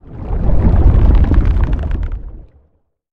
Sfx_amb_treespire_ventgarden_mouthclose_01.ogg